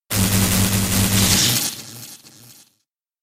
Sound Effects